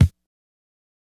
BattleCatTapeKick.wav